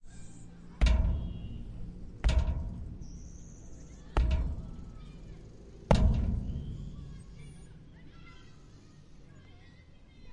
描述：使用一块金属制成的声音
Tag: 命中 隆隆声 钣金